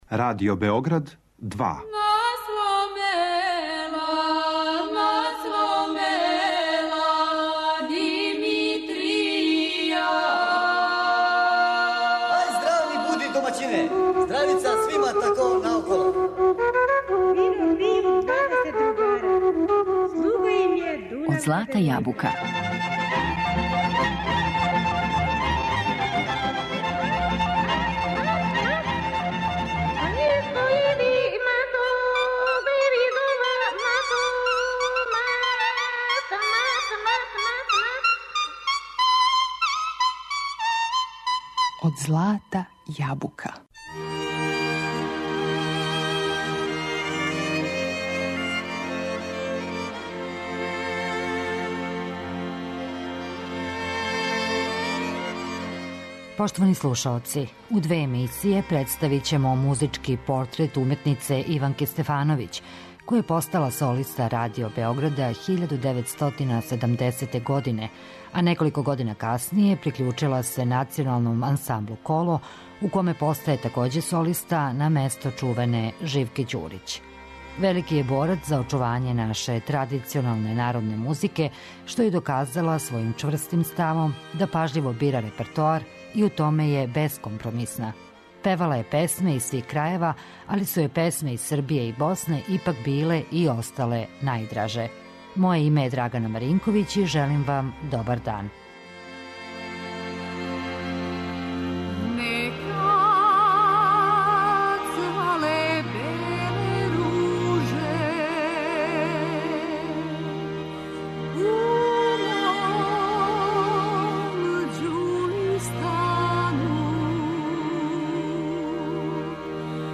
Велики је борац за очување традиционалне народне песме што је доказала чврстим ставом и пажљивим избором репертоара.